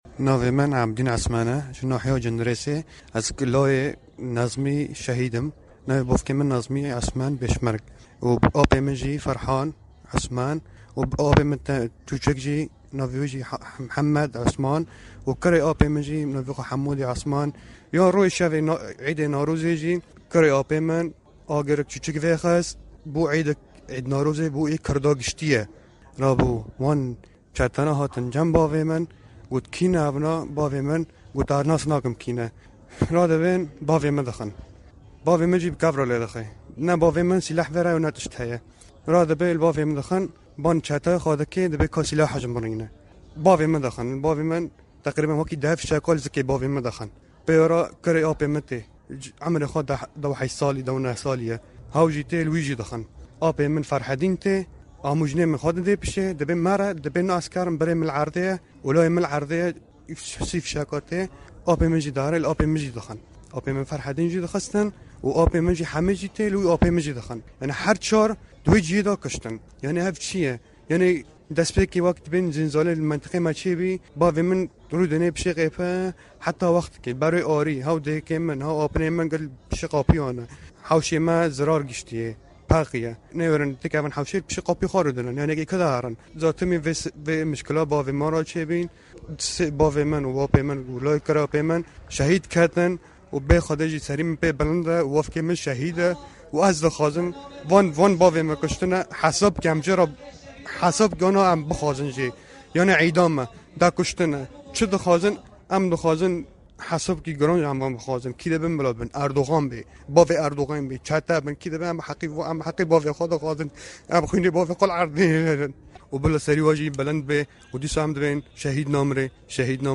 لێدوان یکەسوکاری قوربانییەکان بۆ دەنگی ئەمەریکا:
لێدوانی کەسوکاری قوربانییەکان